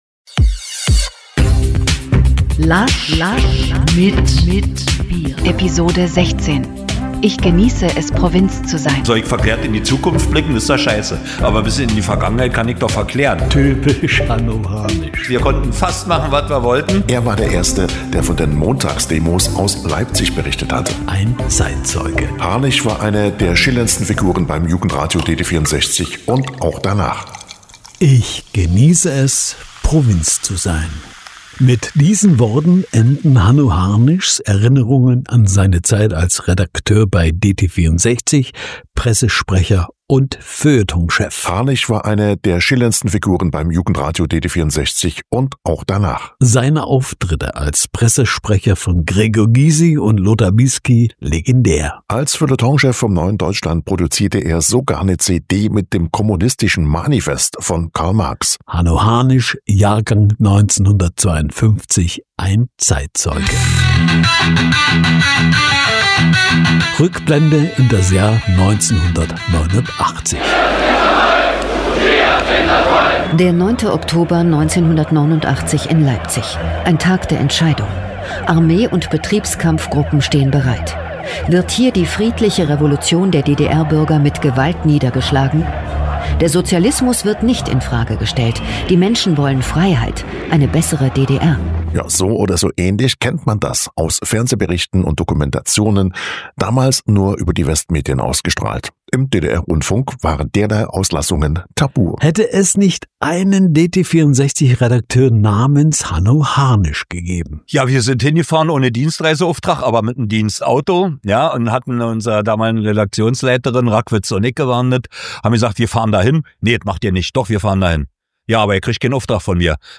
…erinnern mit Tondokumenten an den 9. Oktober 1989